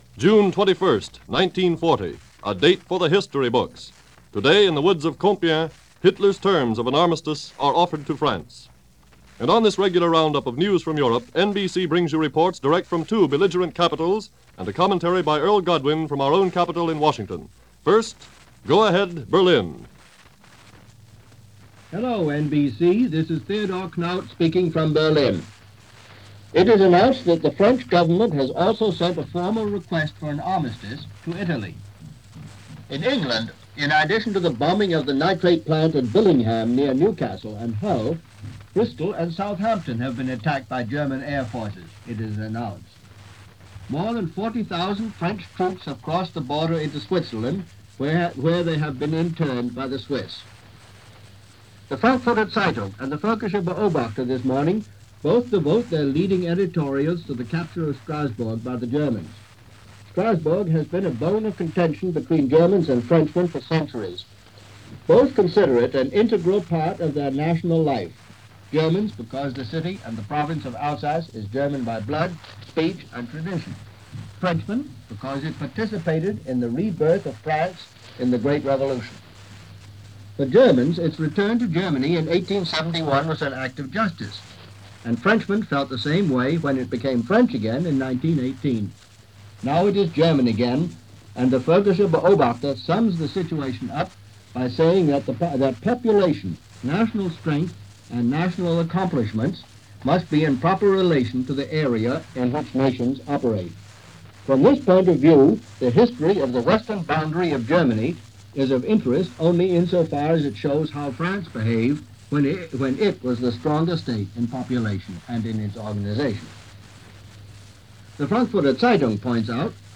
Armistice Is Negotiated - Conditions Are Read - Hitler Is Happy - June 21, 1940 - reports from France and Germany on this day in 1940.
– News – June 21, 1940 – NBC Radio – Gordon Skene Sound Collection –